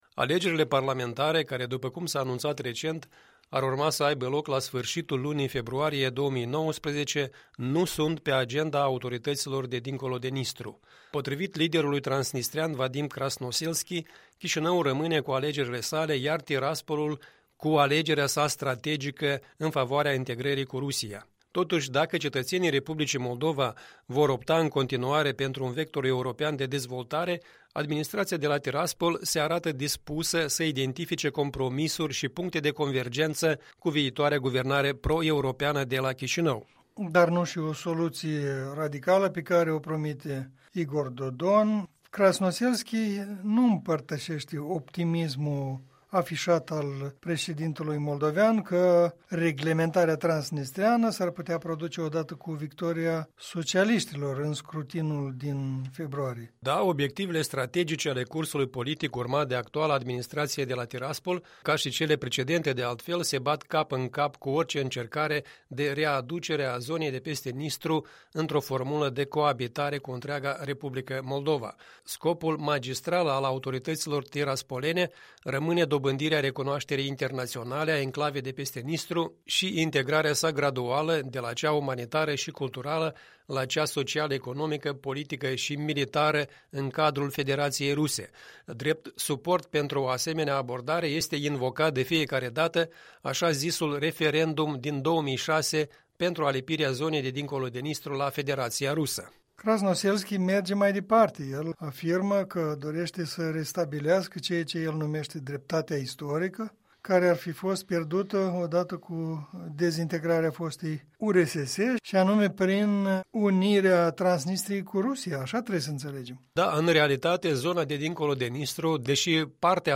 Un punct de vedere săptămânal în dialog despre ultimele evoluții în reglementarea transnistreană